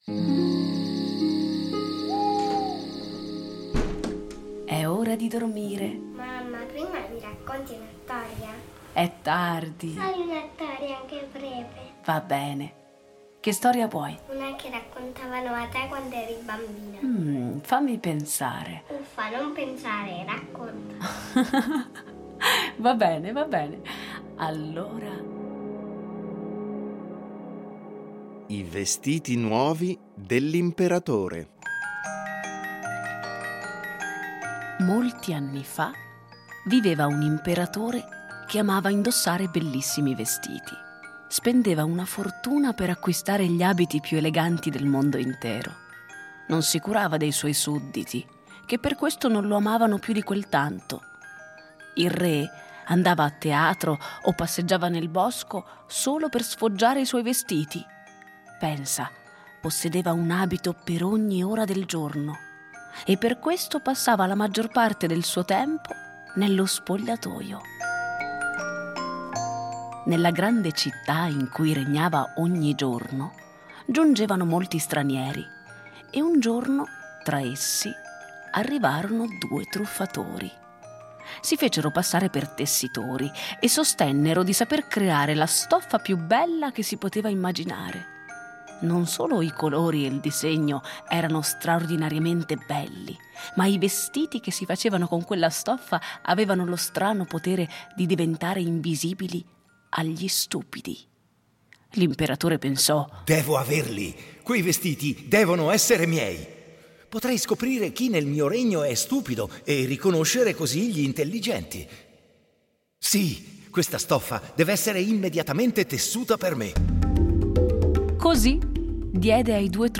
Fiabe
A partire dai testi originali un adattamento radiofonico per fare vivere ai bambini storie conosciute, ma un po’ dimenticate.